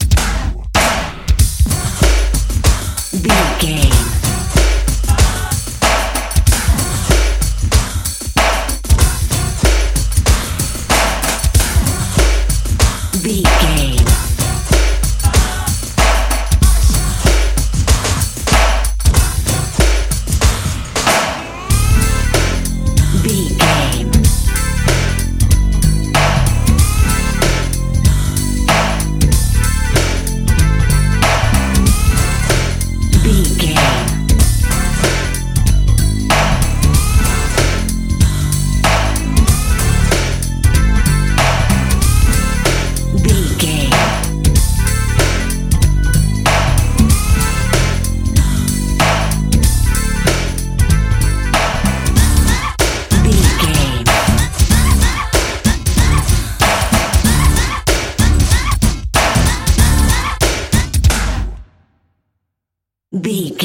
Aeolian/Minor
D
drum machine
synthesiser
Eurodance